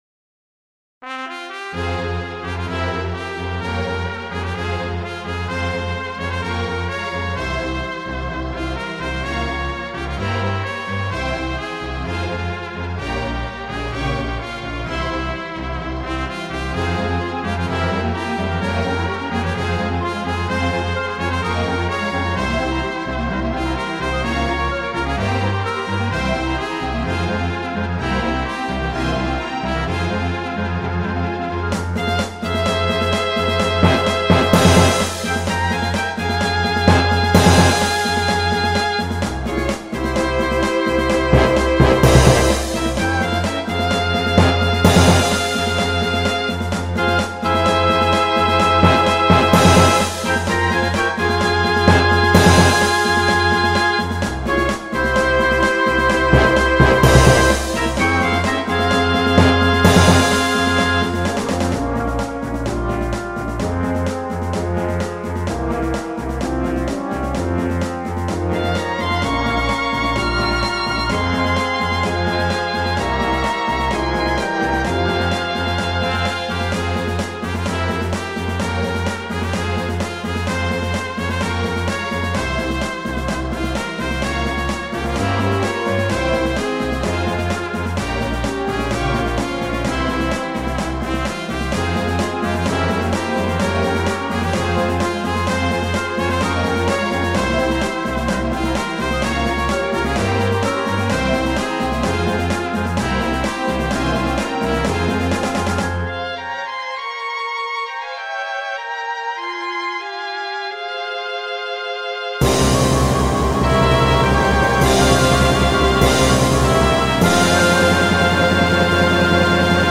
クラシックファンタジーロング